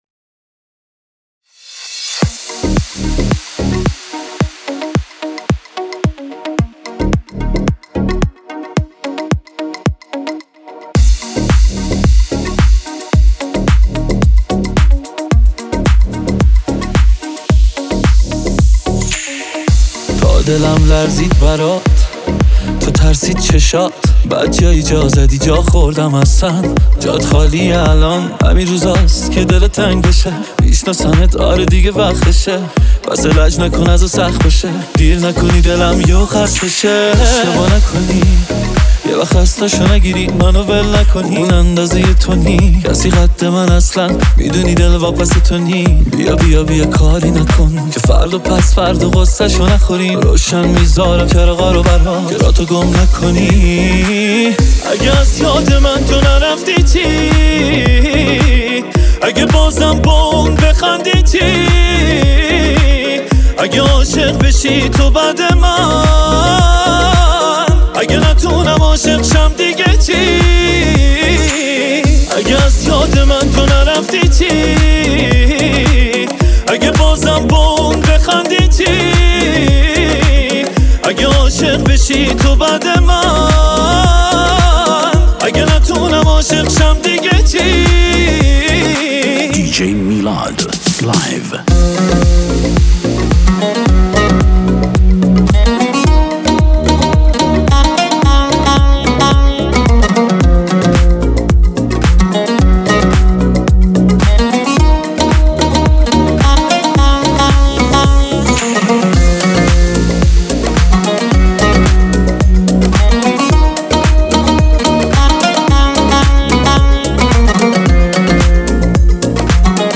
27 می 2024 دانلود ریمیکس